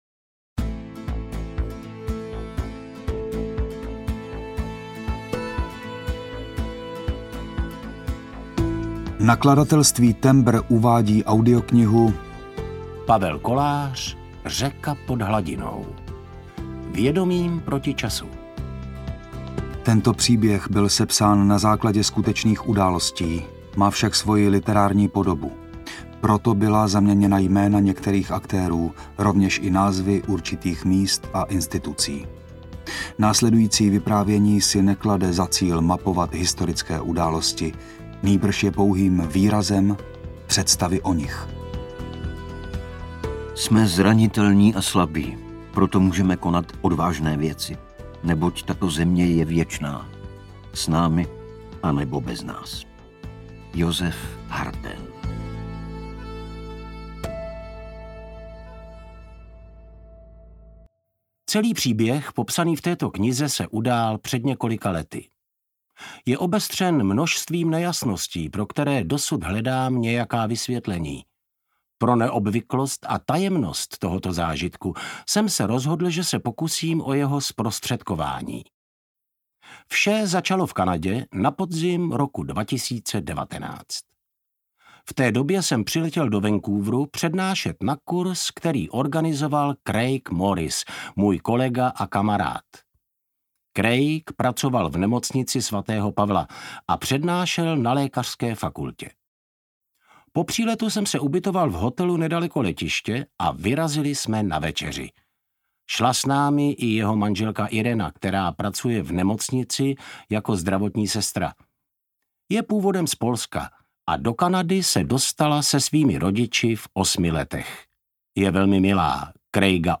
Řeka pod hladinou audiokniha
Ukázka z knihy
reka-pod-hladinou-audiokniha